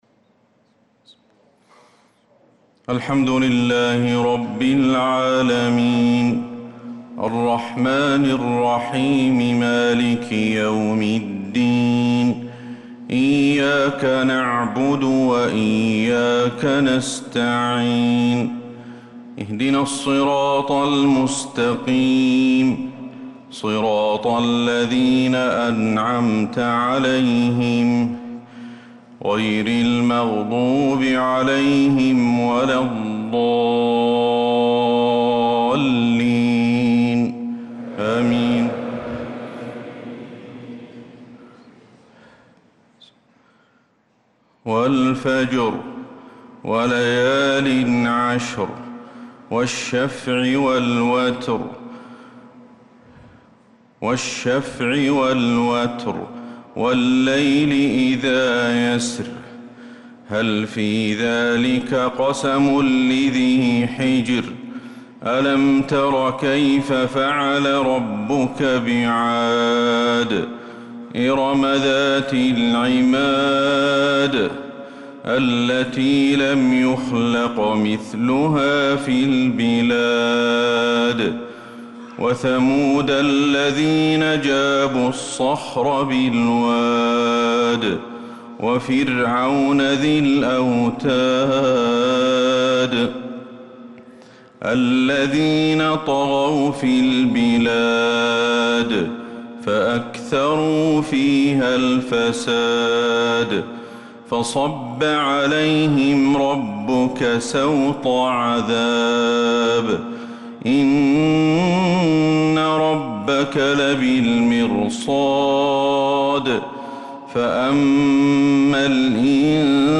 صلاة الفجر للقارئ أحمد الحذيفي 1 ذو الحجة 1445 هـ
تِلَاوَات الْحَرَمَيْن .